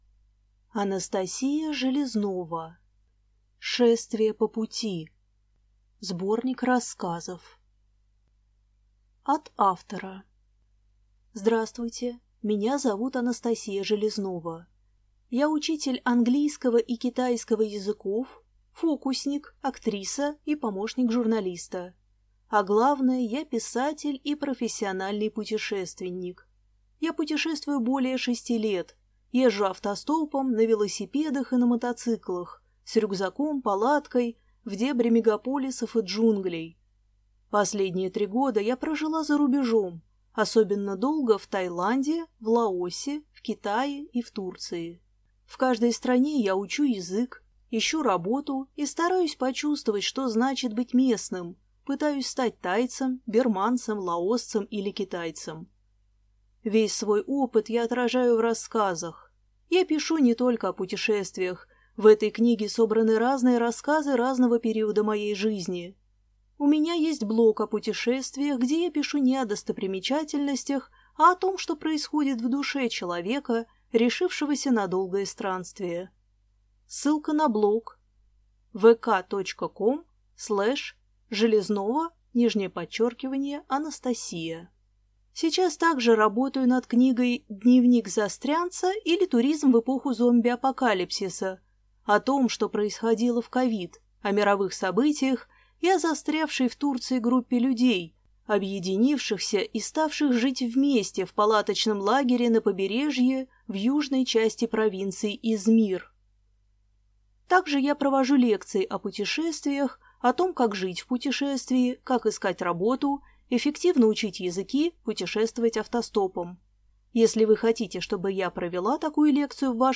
Аудиокнига Шествие по пути. Сборник рассказов | Библиотека аудиокниг